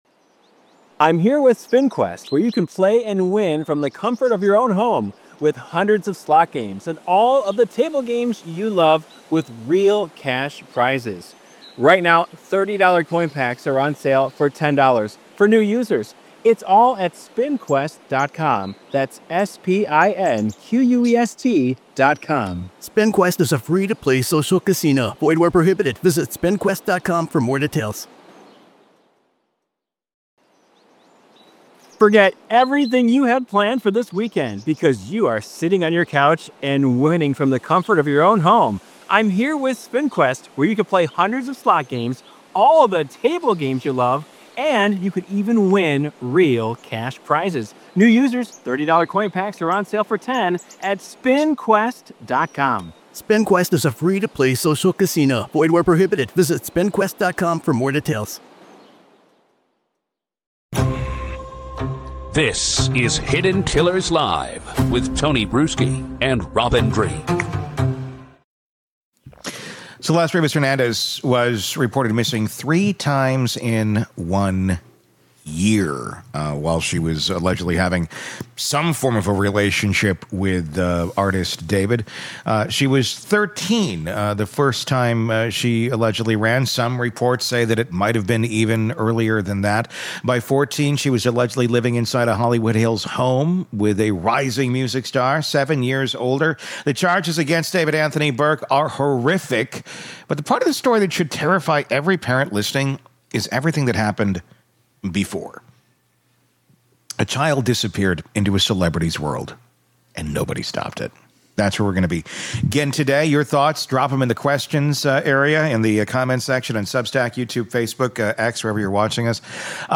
Hidden Killers Live! Daily True Crime News & Breakdowns / D4VD: Three Parts.